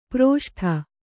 ♪ proṣṭha